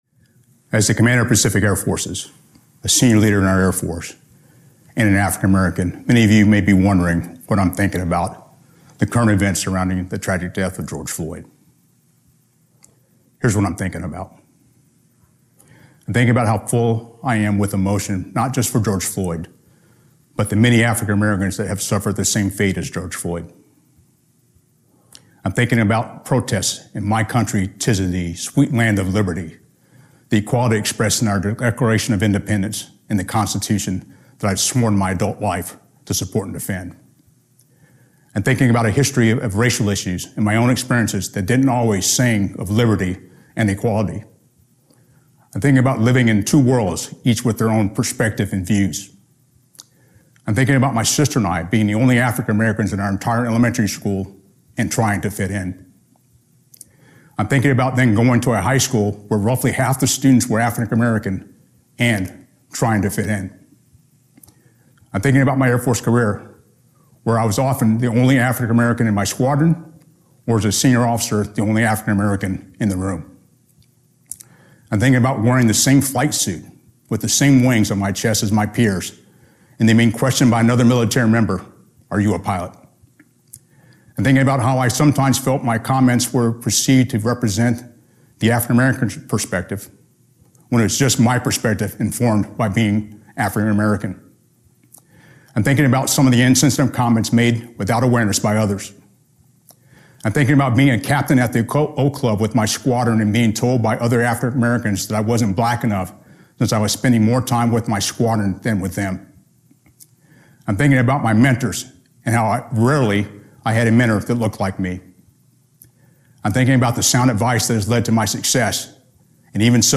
Audio AR-XE mp3 of Address
Audio Note: AR-XE = American Rhetoric Extreme Enhancement